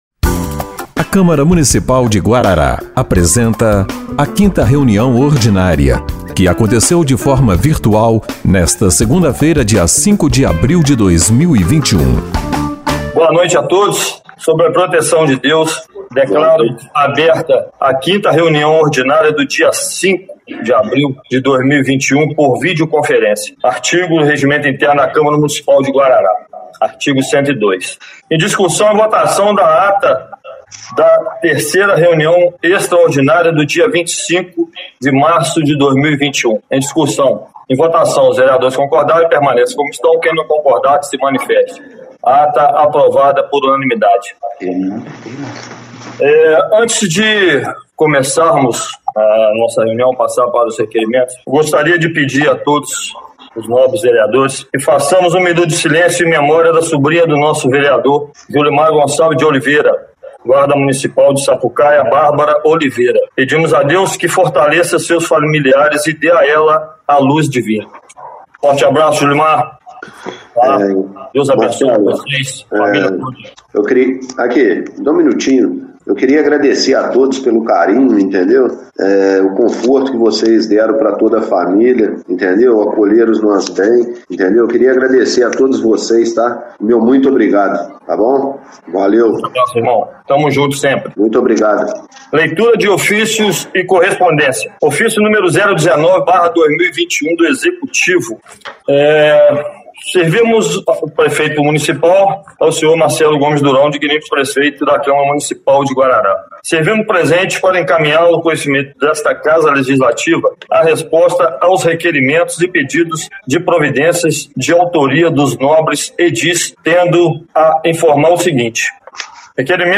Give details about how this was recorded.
5ª Reunião Ordinária de 05/04/2021